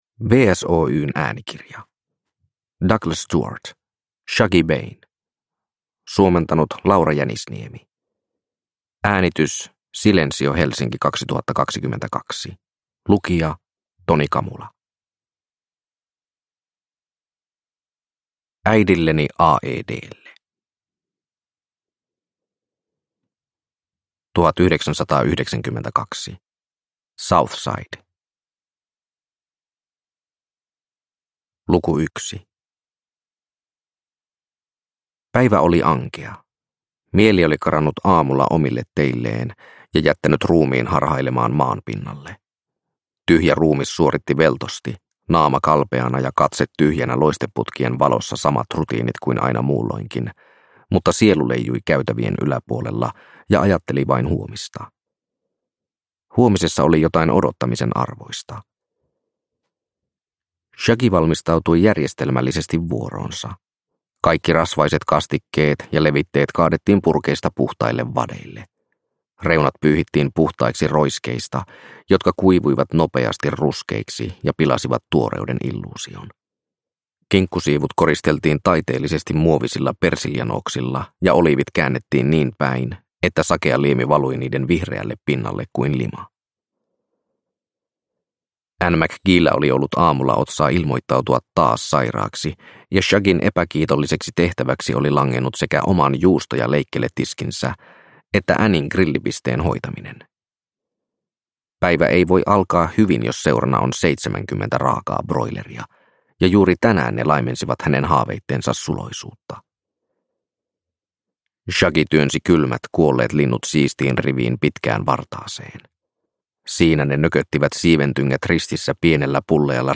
Shuggie Bain – Ljudbok – Laddas ner